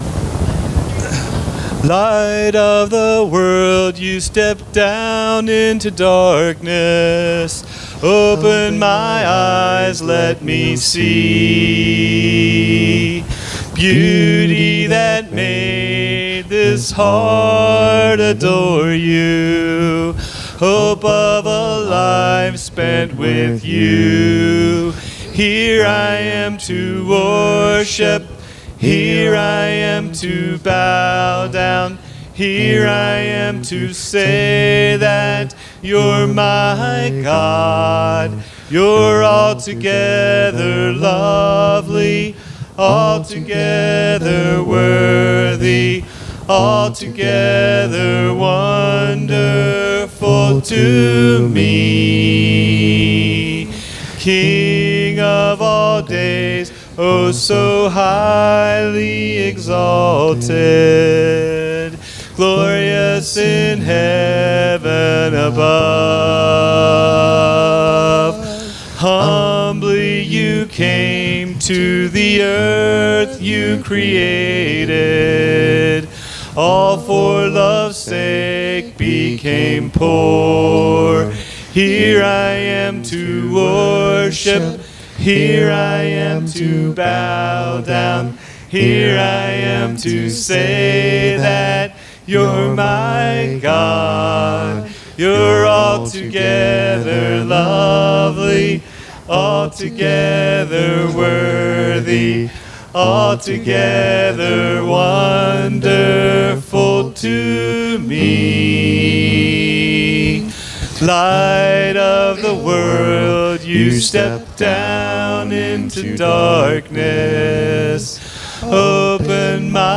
PLEASE NOTE that there is static from around middle of sermon to end when sound ceases completely.